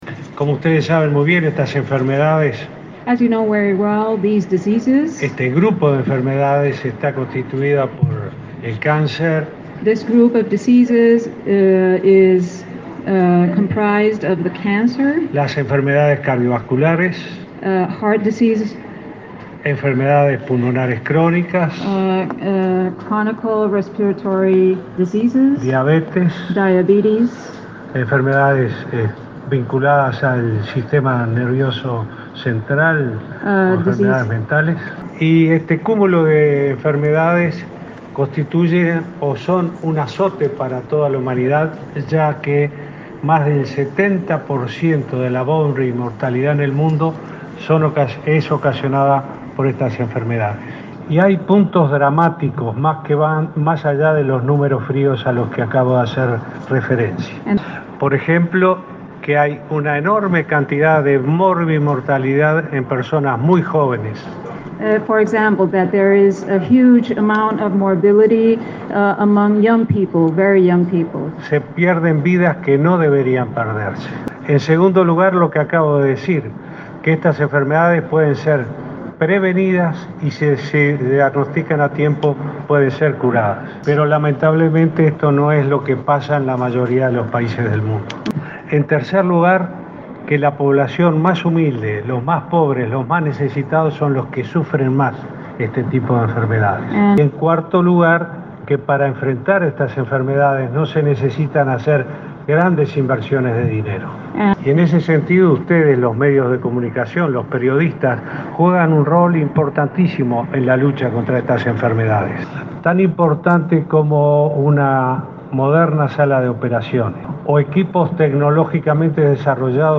El presidente Tabaré Vázquez brindó este jueves una conferencia de prensa, tras su disertación en la Reunión de Alto Nivel sobre Enfermedades No Transmisibles (ENT) de la OMS, en el marco de la Asamblea General de Naciones Unidas. El mandatario recalcó que para enfrentarlas no se precisa grandes inversiones y manifestó el rol fundamental que juegan los medios de comunicación en la lucha contra las ENT.